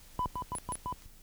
動作音
【オートテープチューニング信号音】
my-GX-R88_auto-tunning.wav